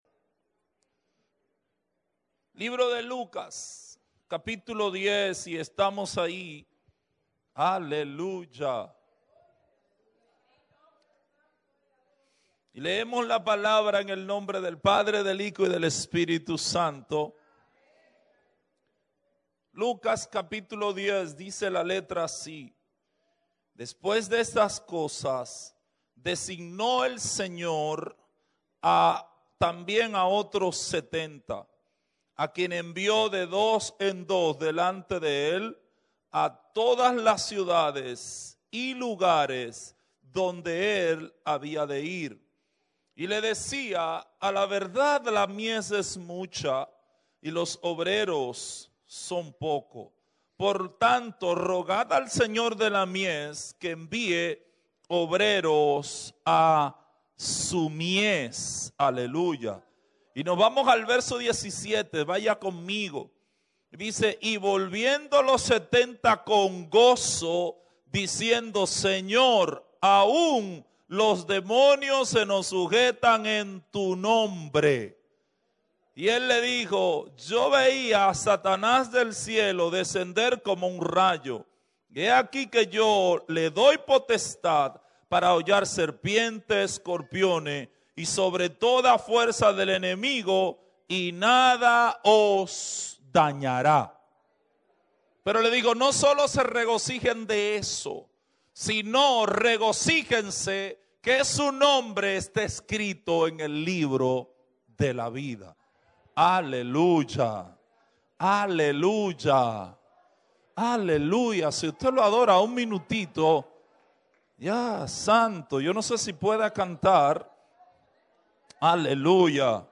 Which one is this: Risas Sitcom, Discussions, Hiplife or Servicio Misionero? Servicio Misionero